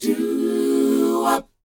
DOWOP D#ED.wav